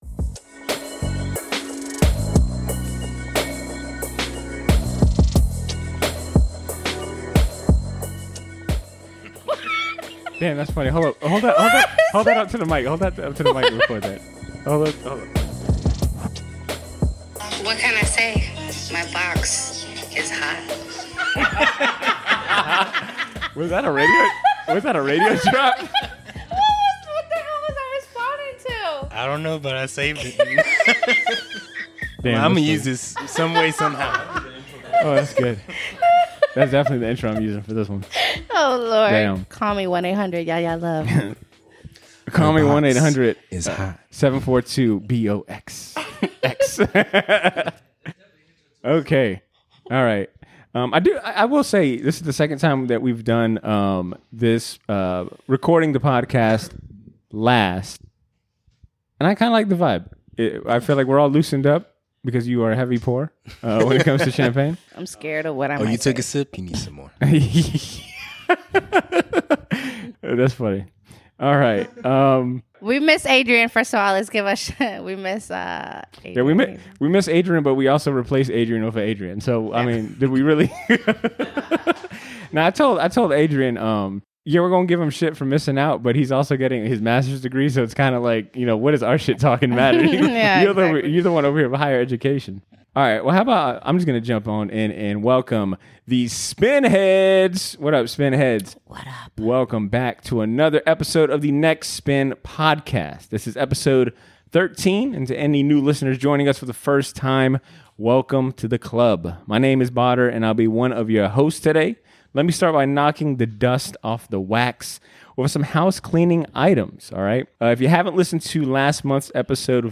Recorded on RSD, with plenty of champagne flowing, this month's theme is centered on Grammy-nominated/winning artists and albums. The crew discuss the award's influence on their personal music tastes, favorite Grammy performances, notable snubs, and more The records we brought (00:45:43):